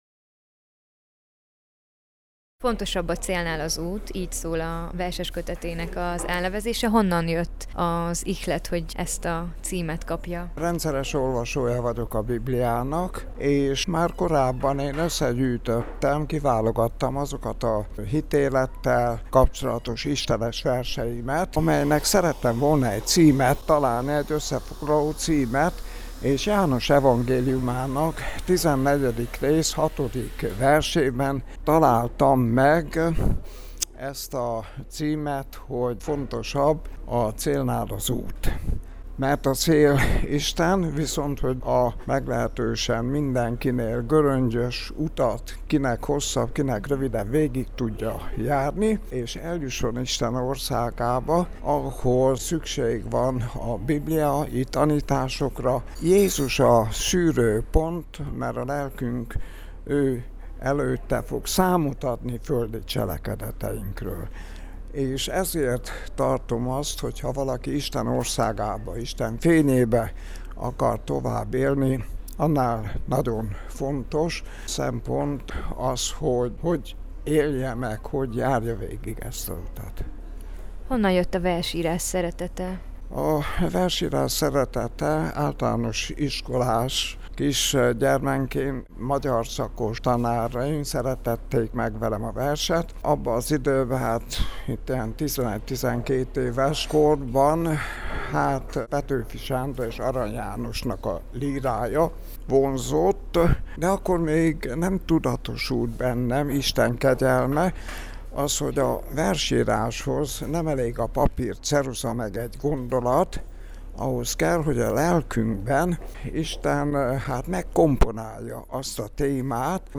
Interjú: